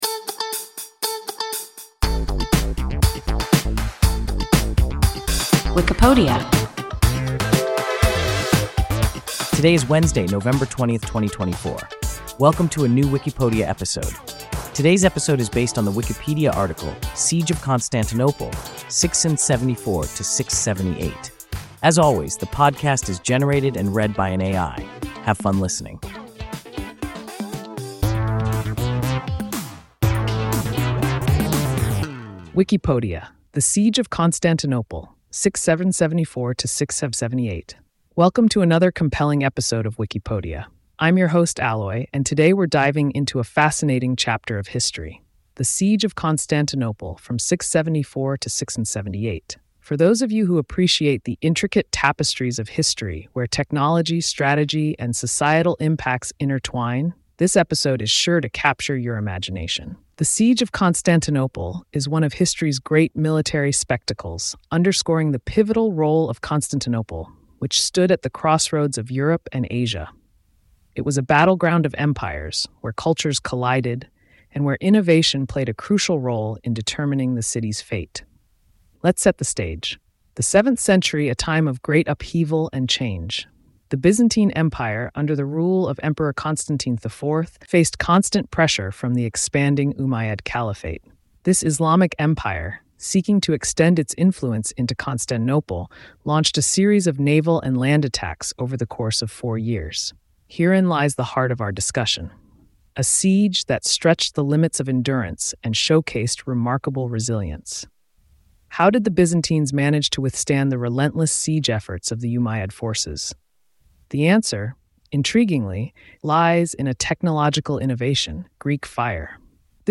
Siege of Constantinople (674–678) – WIKIPODIA – ein KI Podcast